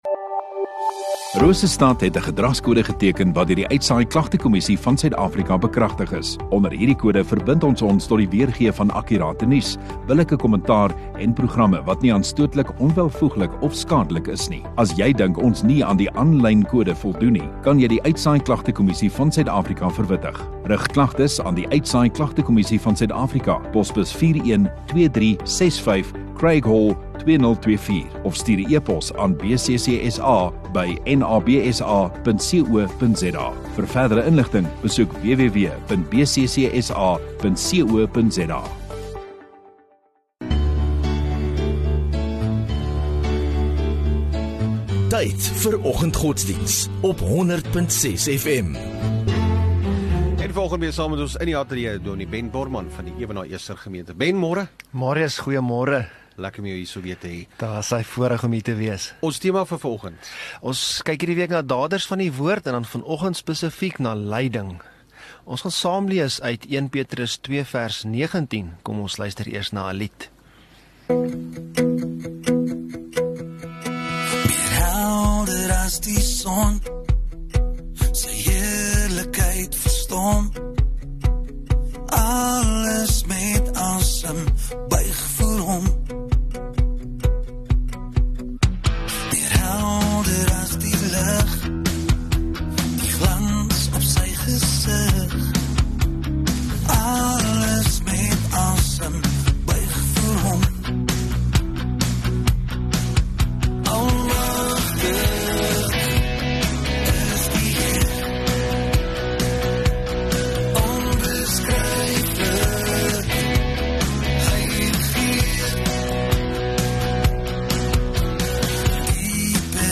16 Jan Donderdag Oggenddiens